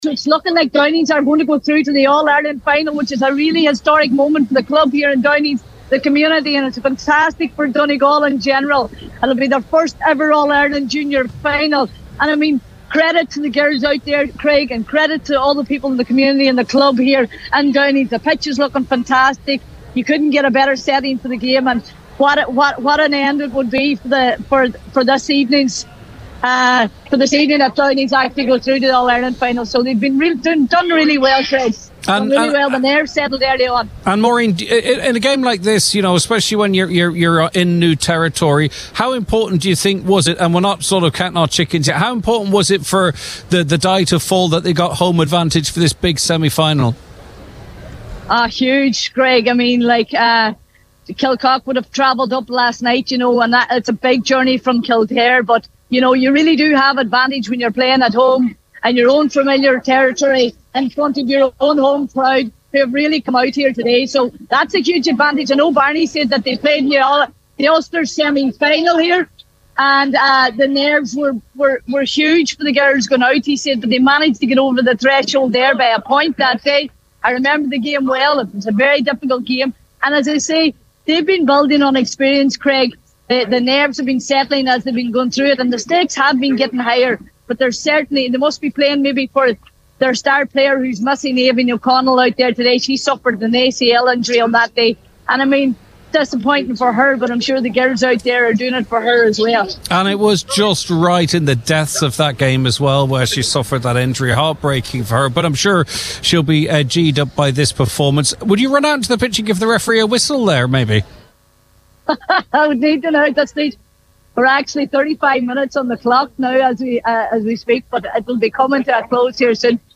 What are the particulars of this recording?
reported live from Downings at full time…